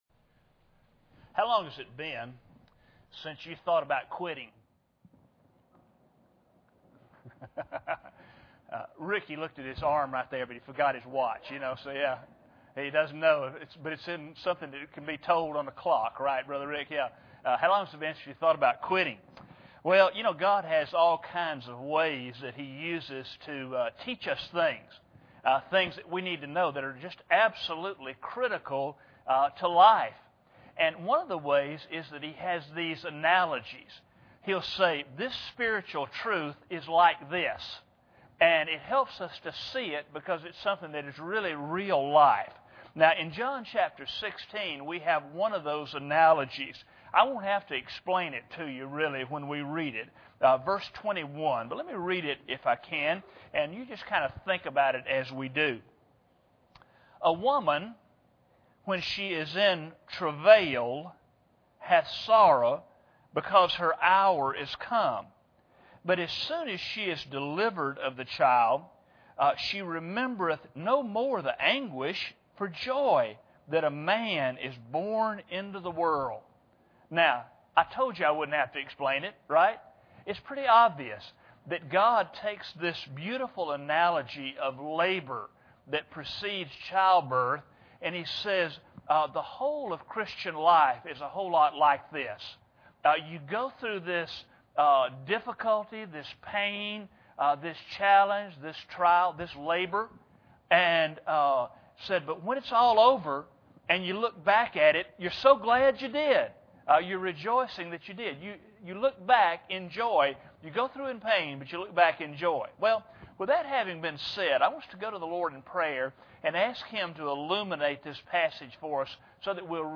John 16:21 Service Type: Sunday Morning Bible Text